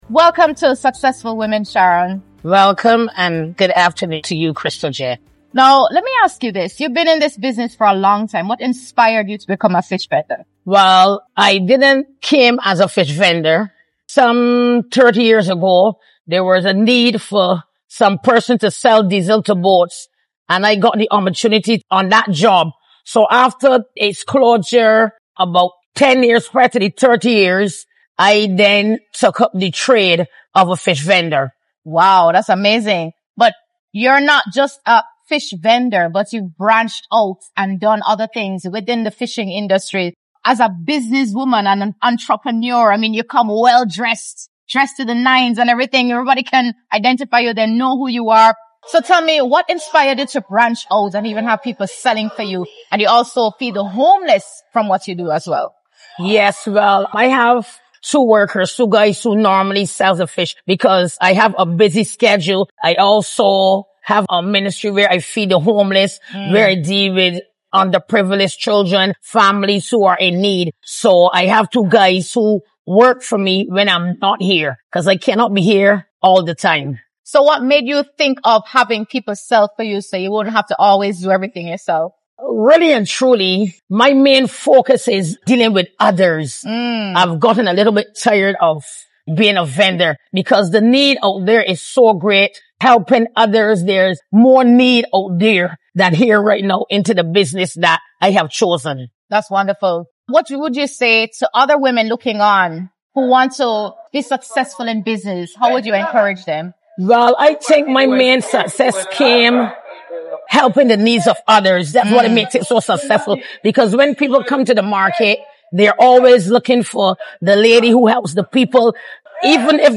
A conversation about resilience, giving back and building through continuous growth.